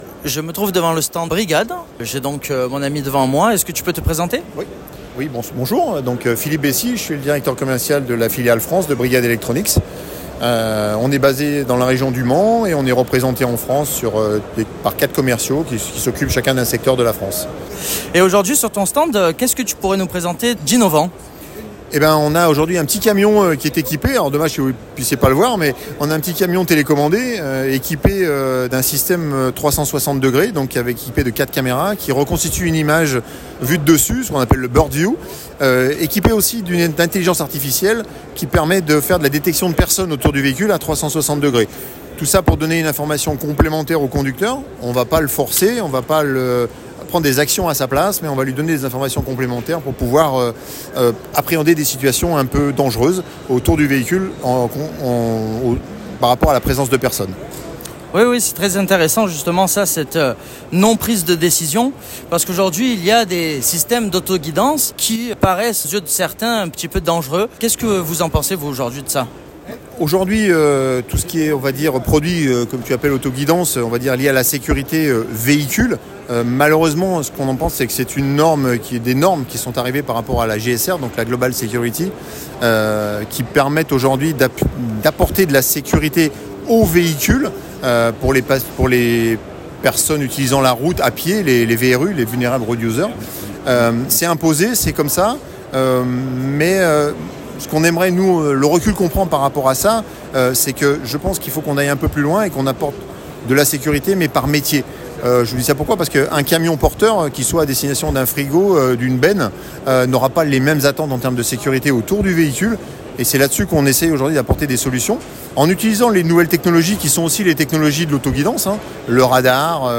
Solutrans 2023
Le 22/11/2023 – EUREXPO Chassieu – SOLUTRANS
INTERVIEW